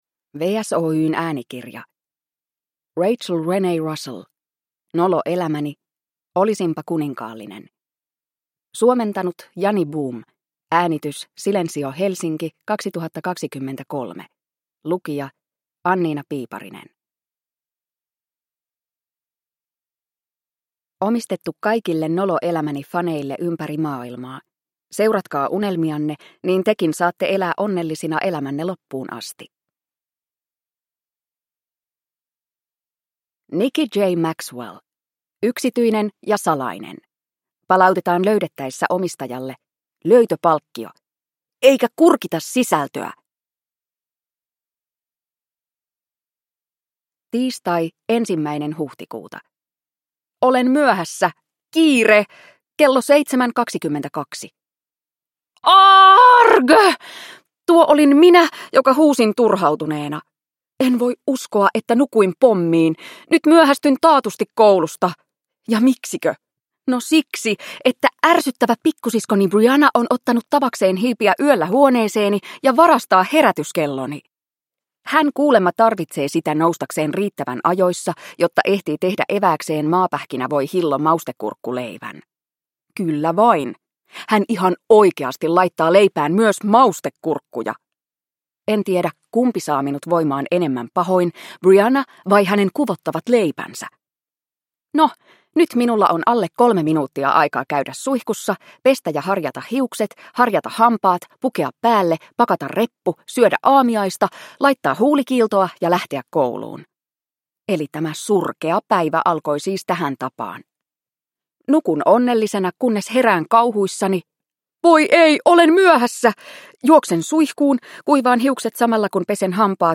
Nolo elämäni: Olisinpa kuninkaallinen – Ljudbok